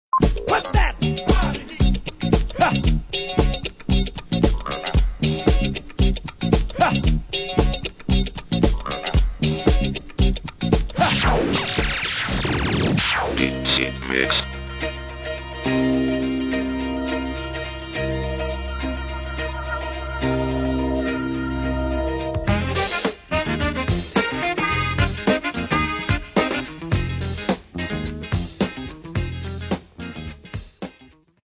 demos...